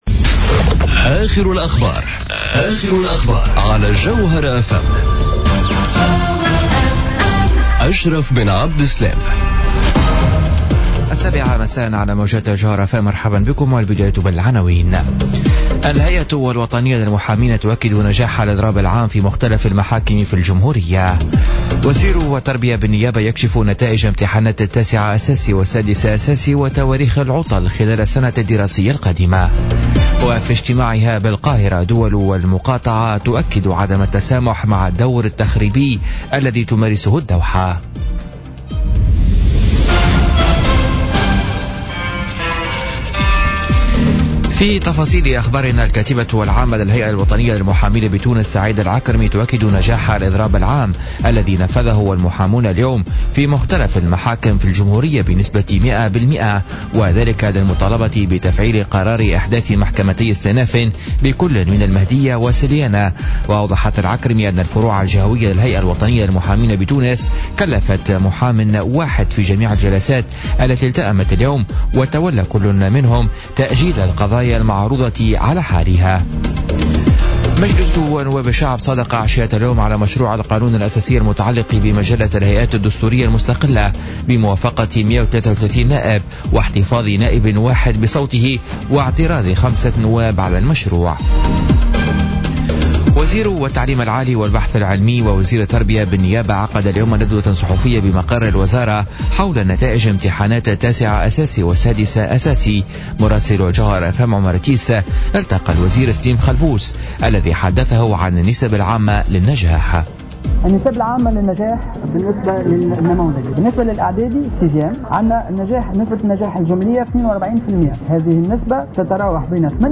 نشرة اخبار السابعة مساء ليوم الأربعاء 05 جويلية 2017